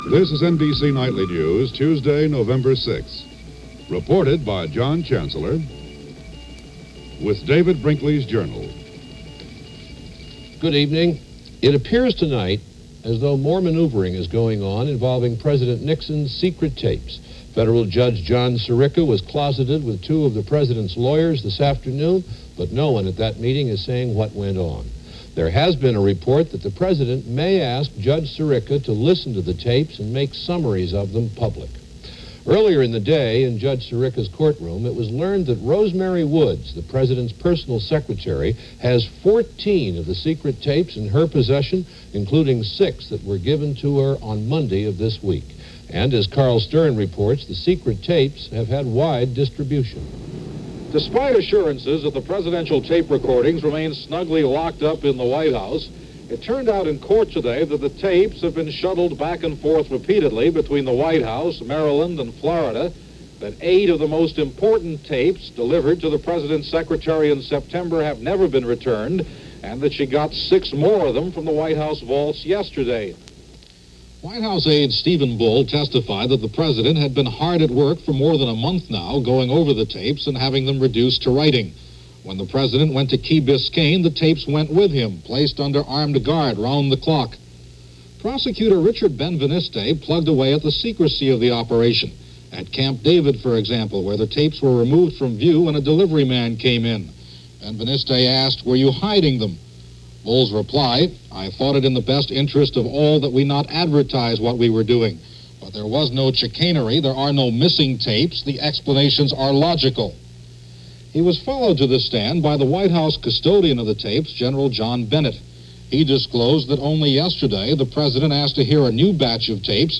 NBC Nightly News (Audio)